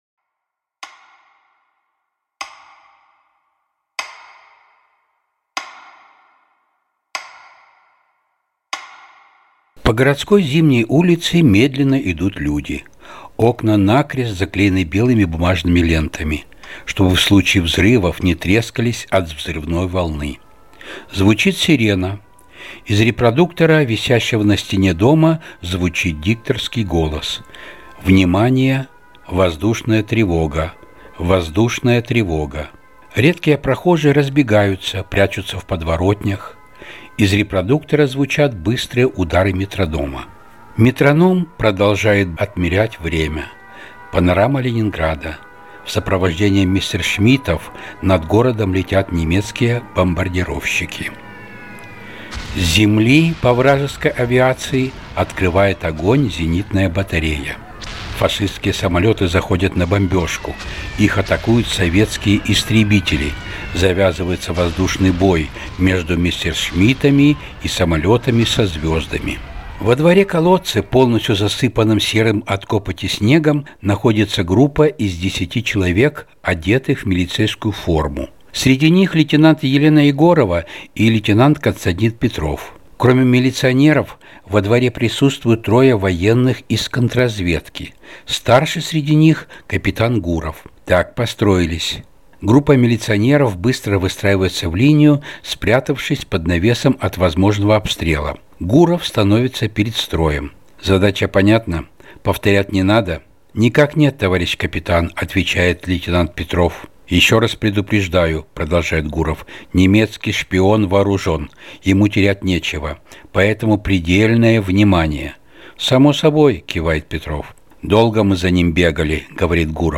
Аудиокнига Сильнее любви.